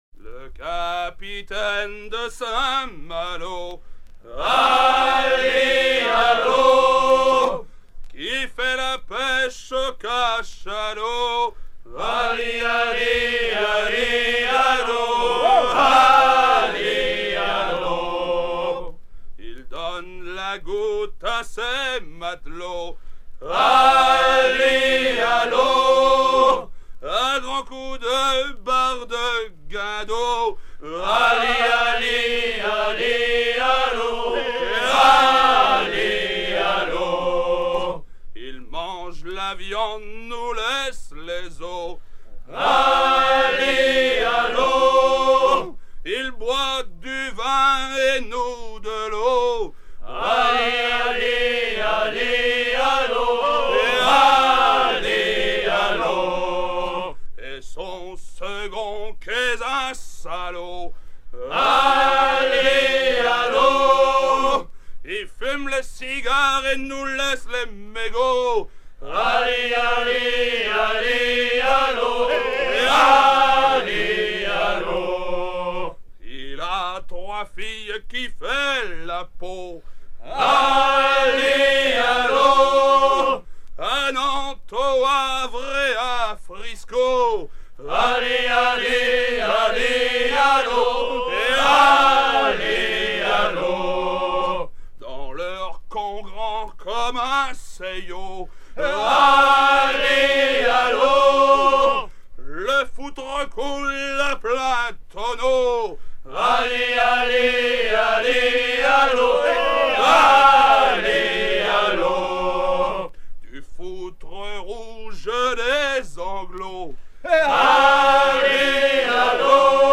gestuel : à hisser à grands coups
Genre laisse